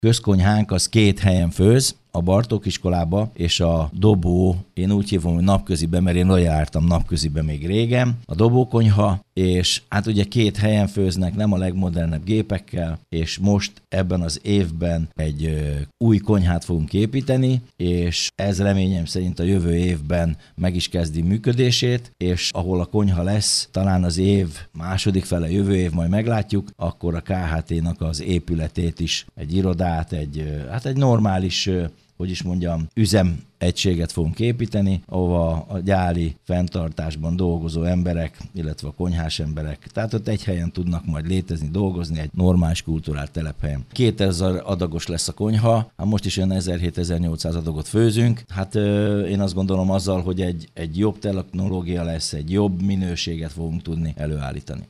Emellett egy új közkonyha is megépül. Pápai Mihály polgármestert hallják.